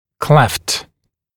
[kleft][клэфт]щель, расщелина; расщепленный, раздвоенный